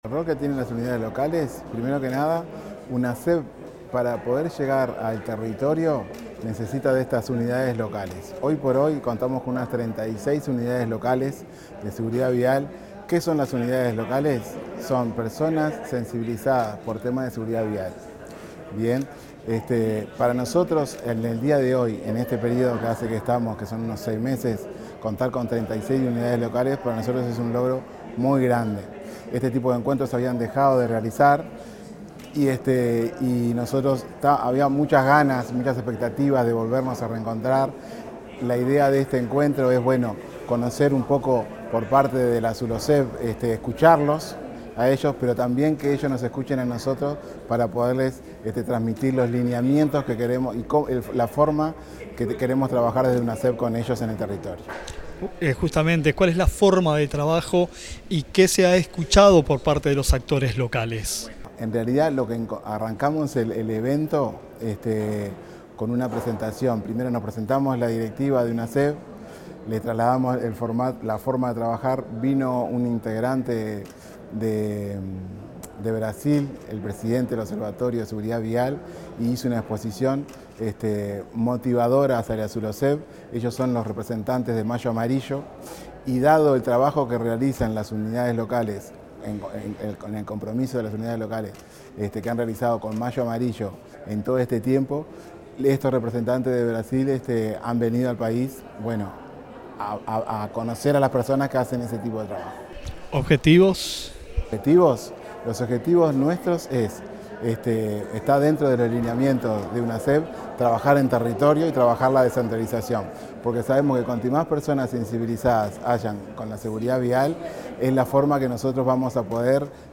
Declaraciones del director de Unasev, Aníbal Pintos